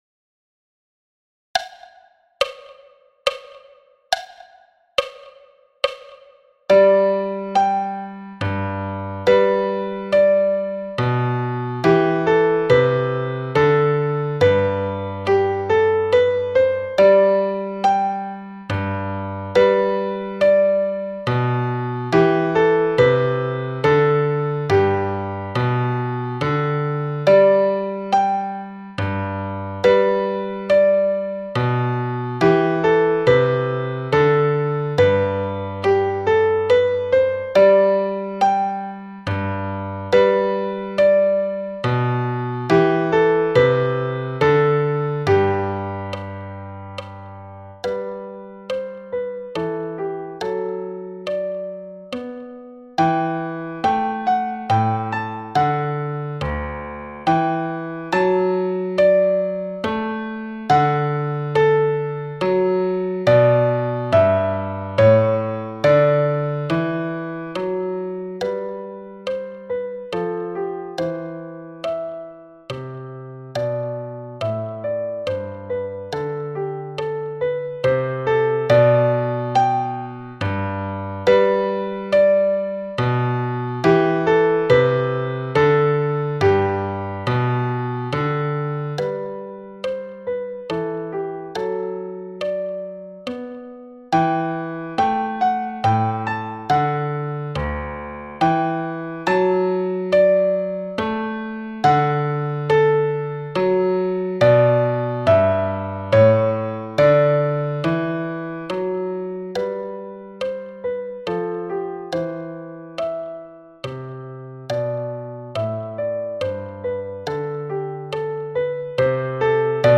Menuet de Telemann – Piano-wb à 70 bpm
Menuet-de-Telemann-Piano-wb-a-70-bpm.mp3